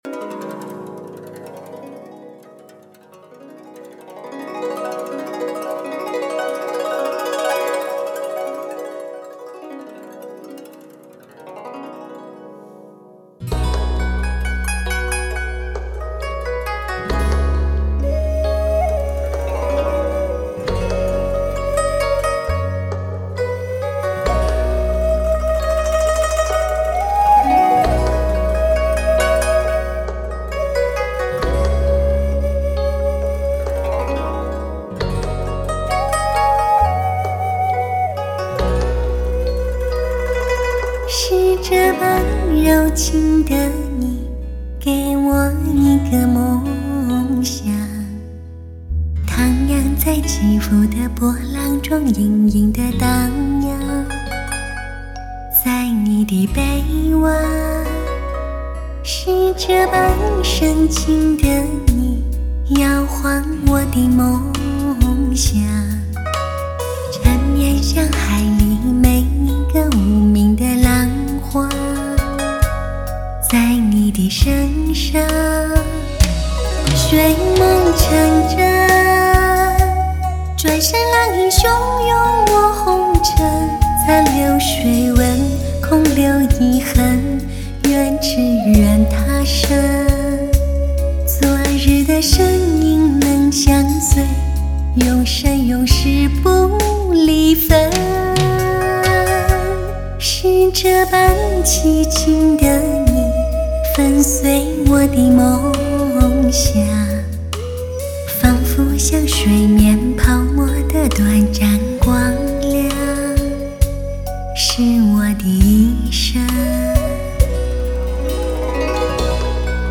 音乐类型: 流行音乐/Pop
演绎细致传神，动态凌厉澎湃，制作完美无瑕，金嗓再次爆响。
精选15首魅力时尚流行靓曲，国内当红发烧靓嗓一流演绎！